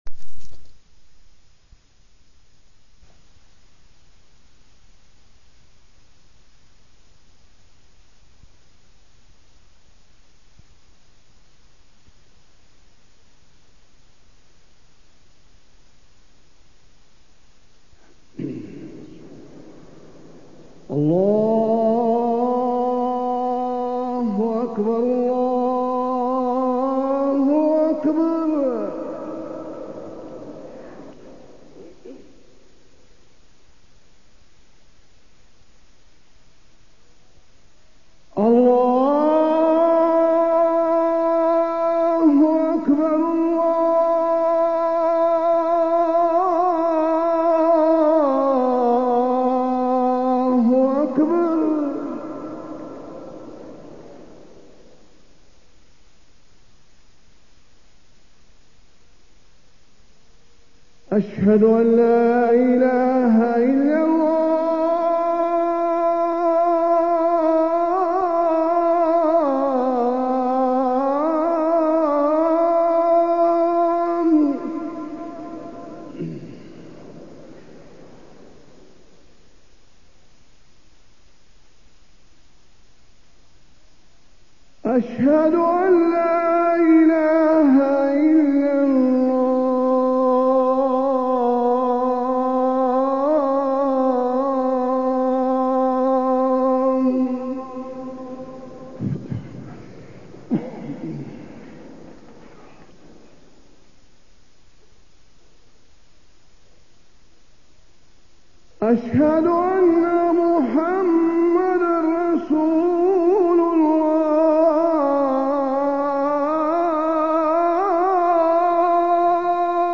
تاريخ النشر ٢٣ ربيع الثاني ١٤٢٥ هـ المكان: المسجد النبوي الشيخ: فضيلة الشيخ د. حسين بن عبدالعزيز آل الشيخ فضيلة الشيخ د. حسين بن عبدالعزيز آل الشيخ الحسد